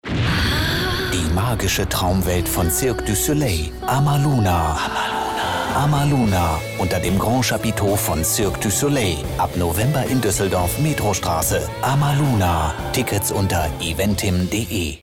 plakativ
Mittel minus (25-45)
Commercial (Werbung)